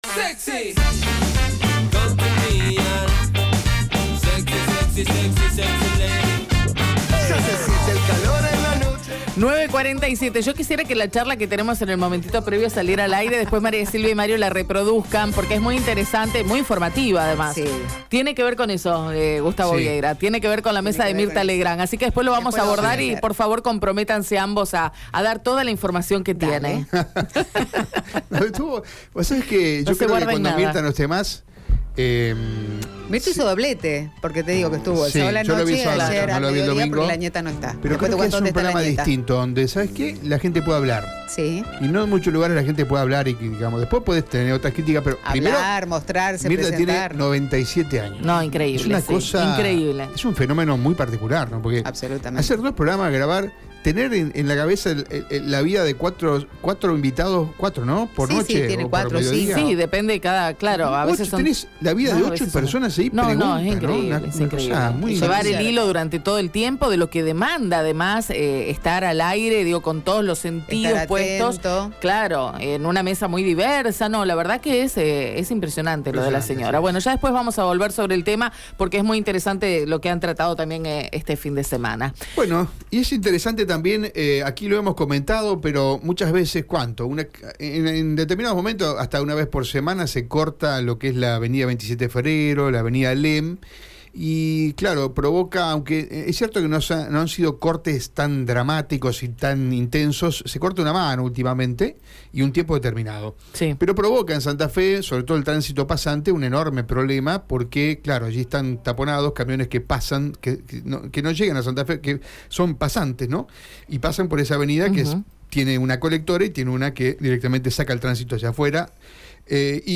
Escucha la palabra de Lucas Simoniello en Radio EME: